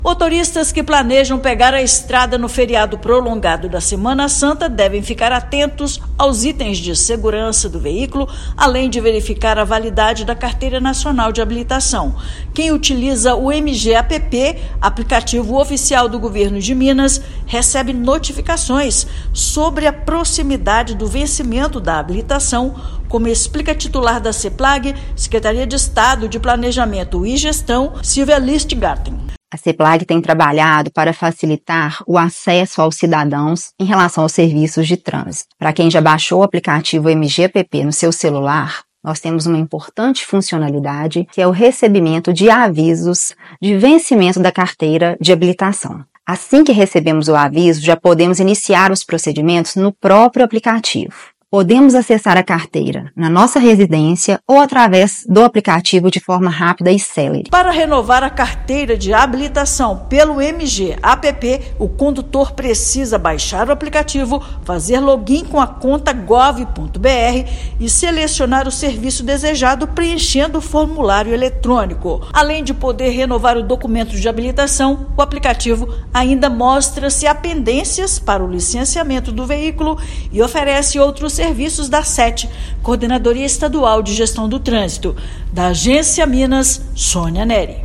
MG App também oferece o acesso ao documento do veículo e outros serviços de trânsito; confira mais dicas para uma viagem segura. Ouça matéria de rádio.